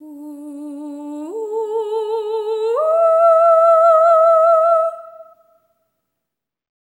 ETHEREAL08-R.wav